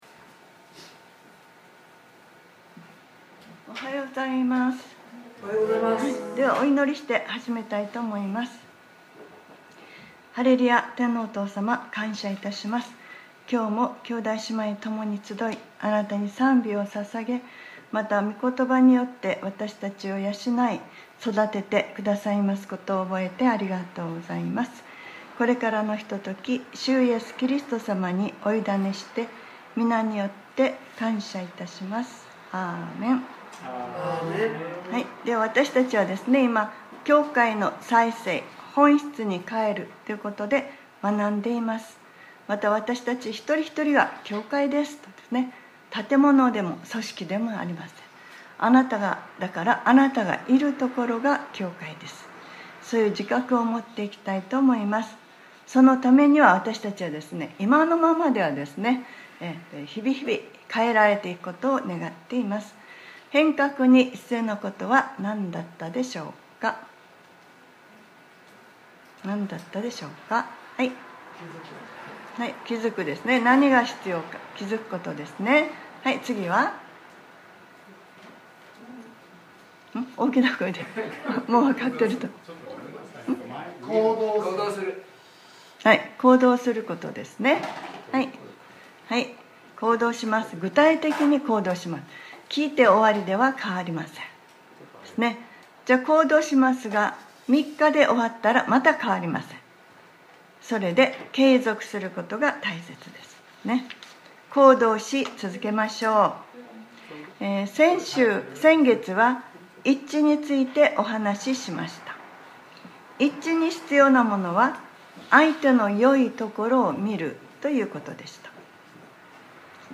2022年11月20日（日）礼拝説教『 教会はキリストのからだ４ 』